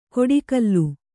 ♪ koḍi kallu